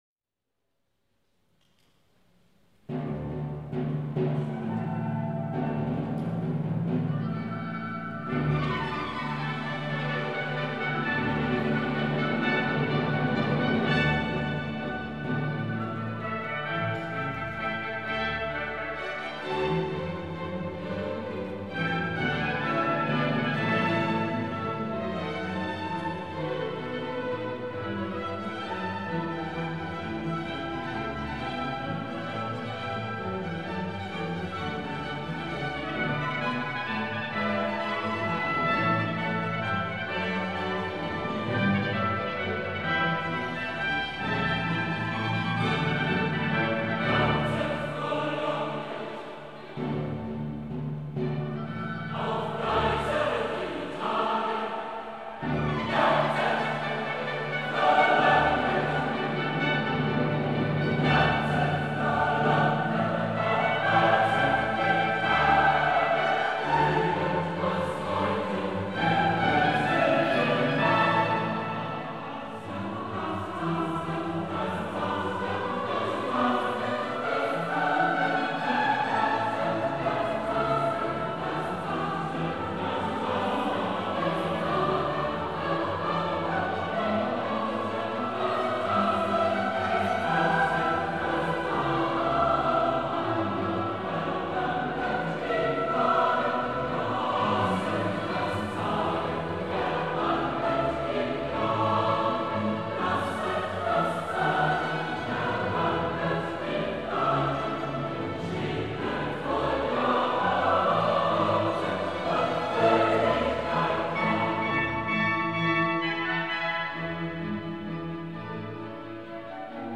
Graf-Zeppelin-Haus - Friedrichshafen - 11. Dezember 2011